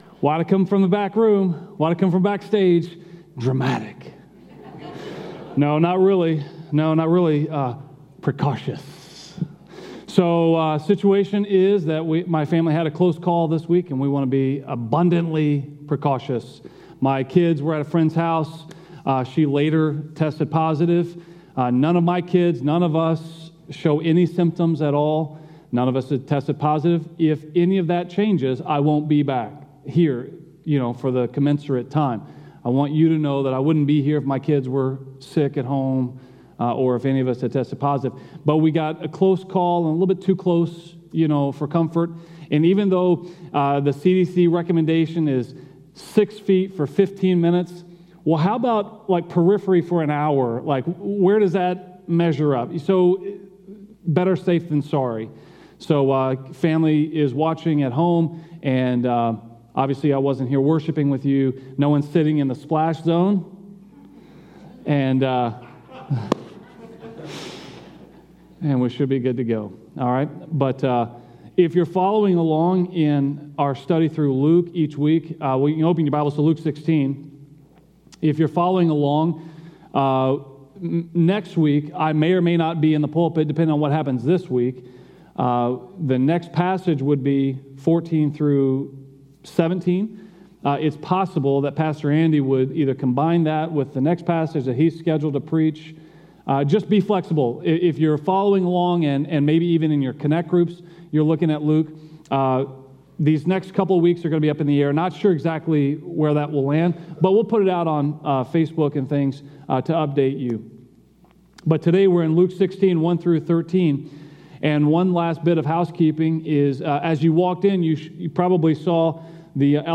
A message from the series "To Seek and To Save."